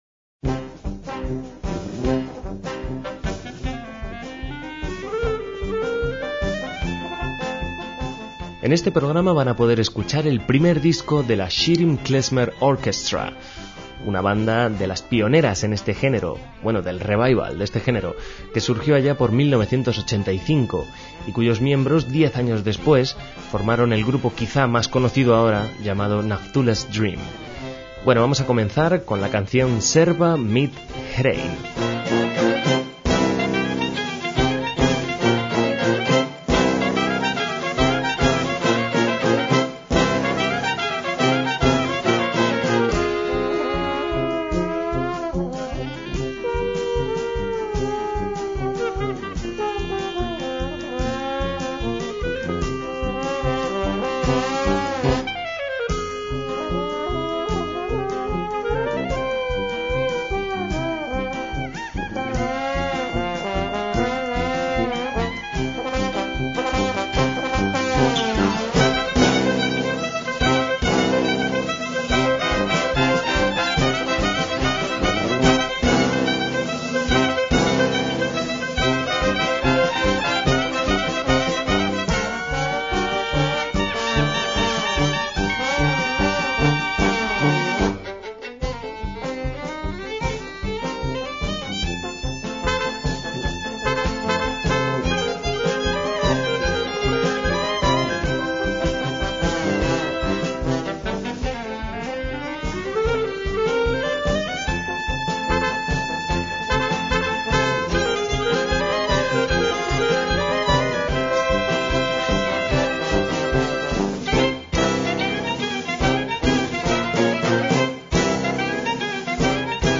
MÚSICA KLEZMER
clarinete